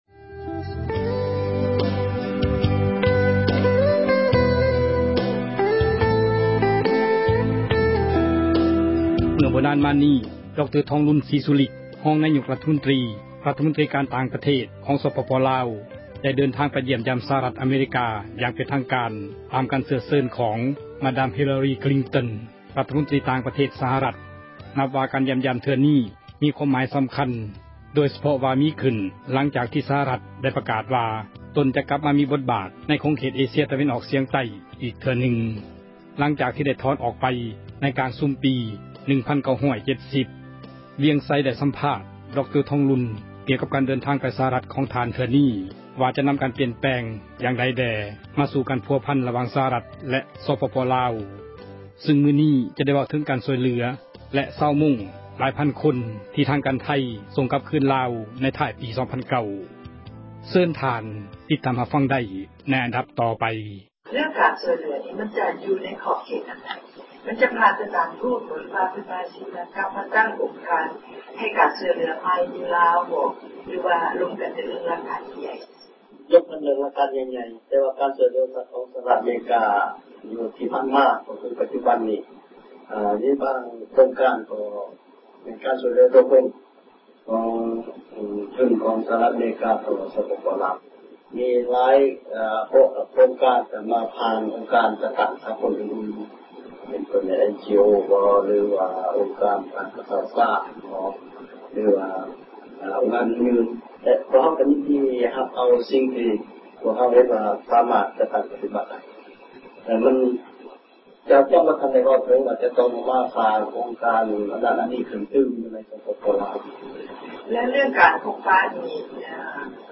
ສັມພາດທ່ານທອງລຸນ ການຢ້ຽມຢາມສະຫະຣັດ(ຕໍ່)